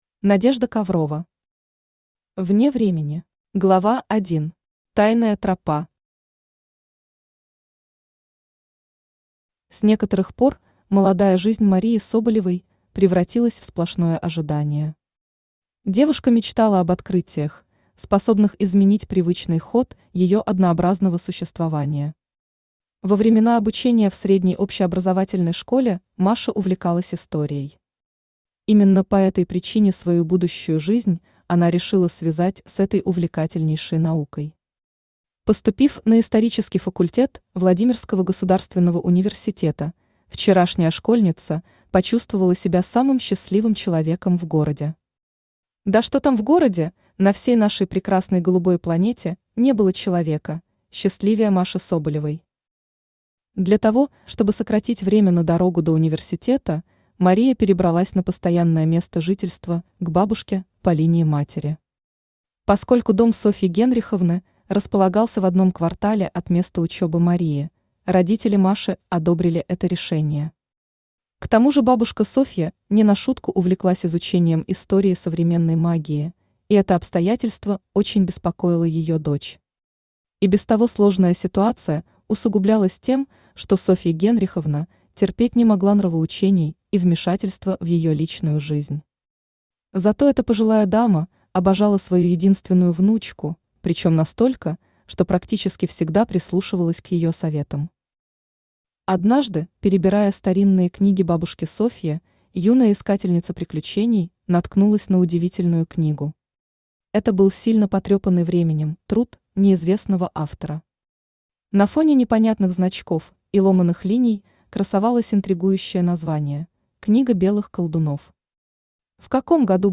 Aудиокнига Вне времени Автор Надежда Коврова Читает аудиокнигу Авточтец ЛитРес.